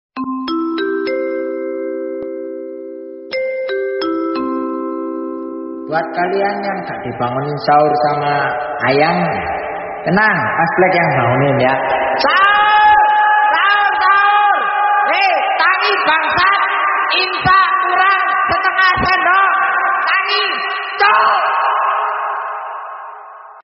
nada alarm imsak yang viral tiktok [download]
nada-alarm-imsak-tiktok-versi-2-id-technolati_com.mp3